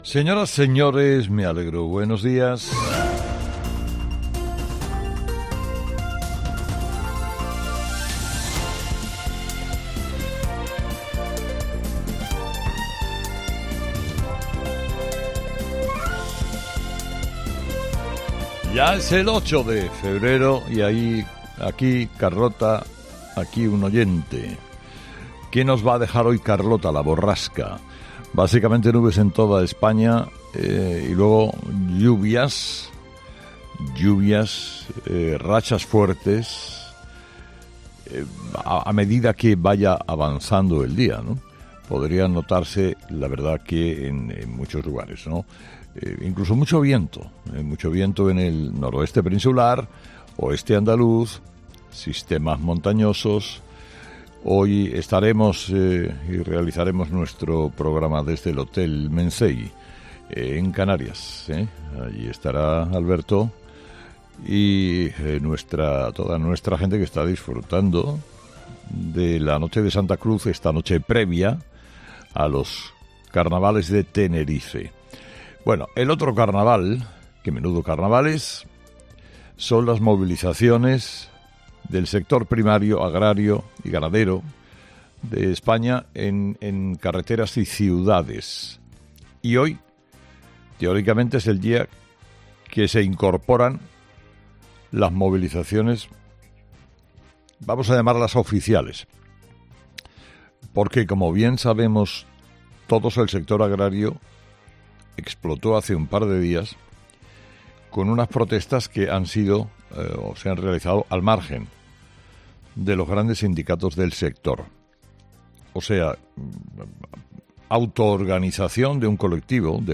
Escucha el análisis de Carlos Herrera a las 06:00 en Herrera en COPE del jueves 8 de febrero